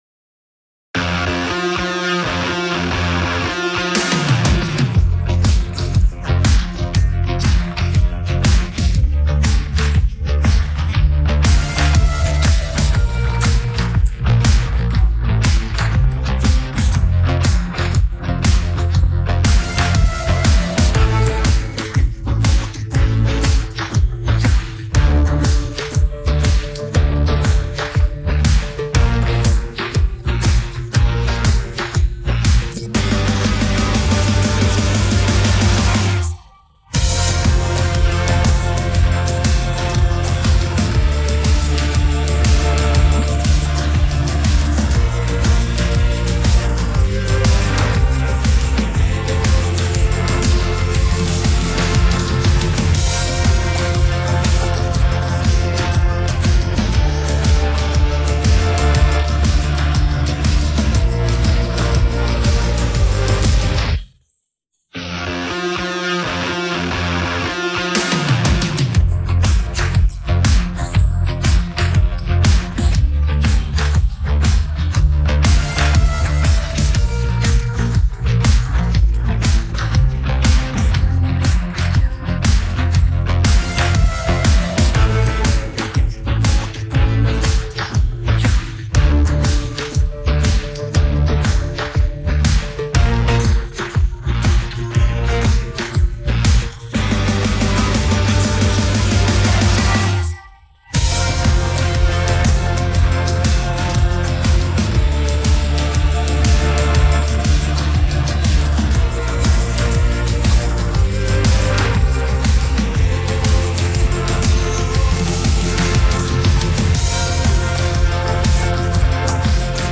scaruca BASE